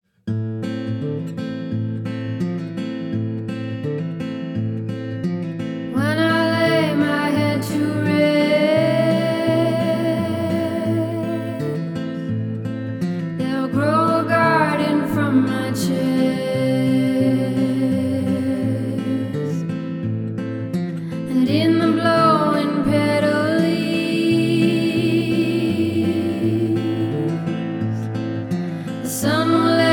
Alternative Folk Singer Songwriter
Жанр: Альтернатива / Фолк